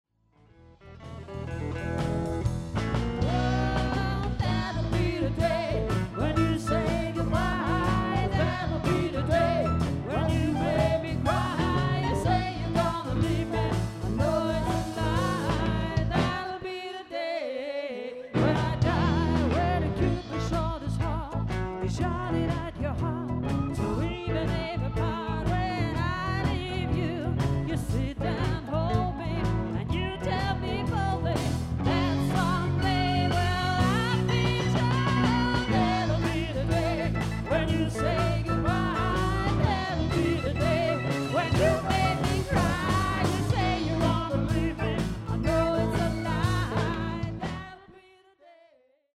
Auf dieser Seite findet Ihr Hörproben unserer Auftritte.